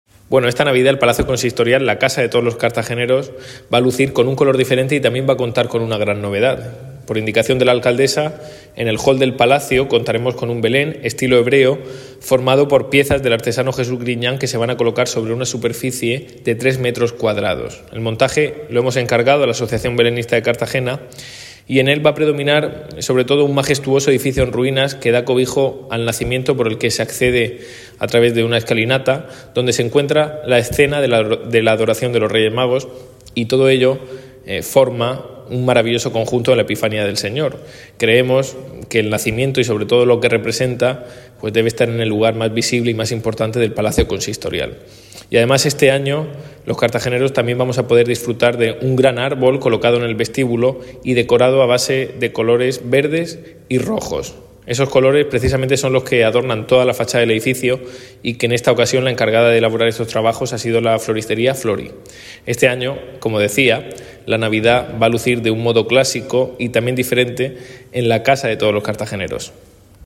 Enlace a Declaraciones del concejal Ignacio Jaúdenes